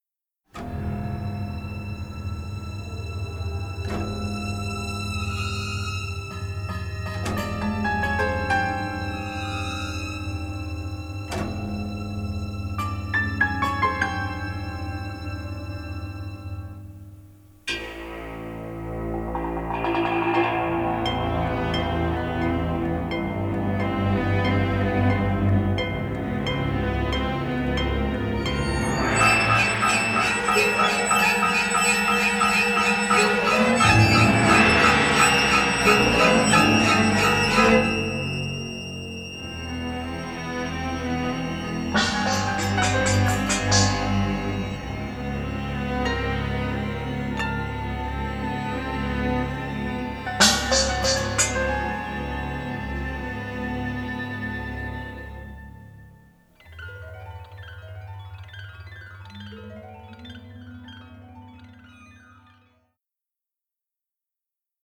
finally erupting into moment of orchestral fury